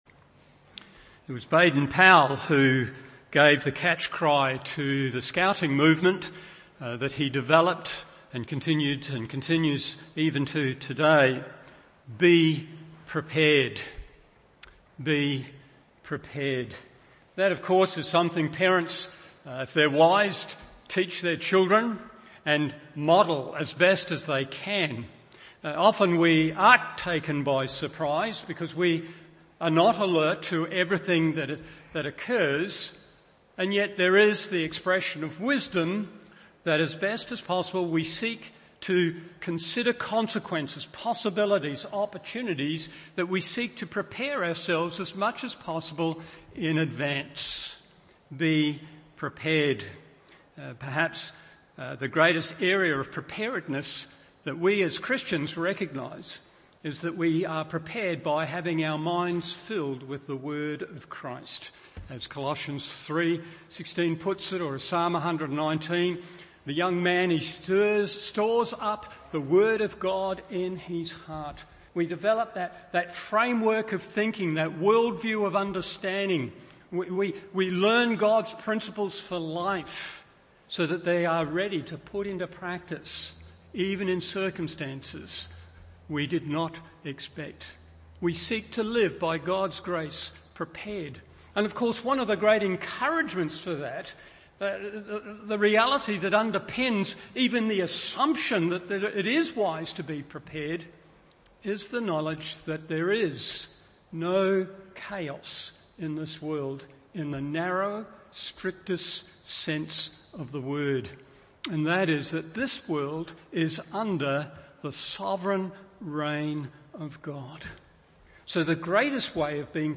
Morning Service Genesis 6:14-7:5 1.